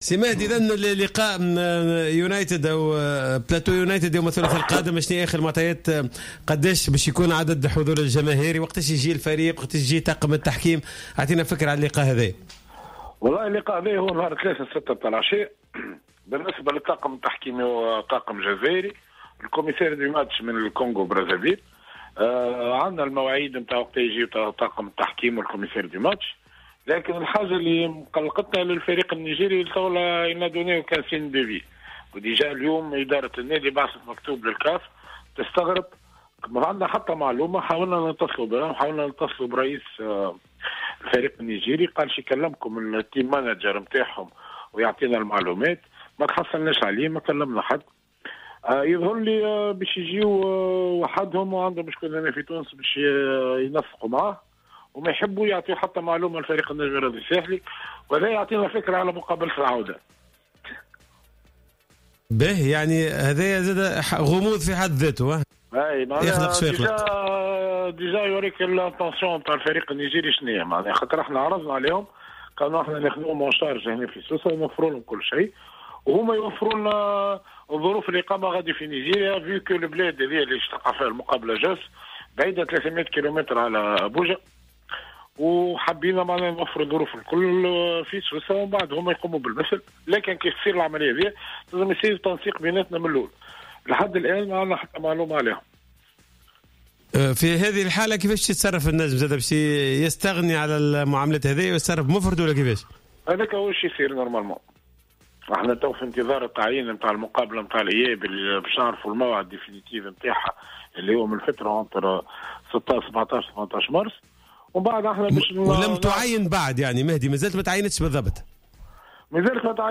خلال مداخلته في برنامج راديو سبور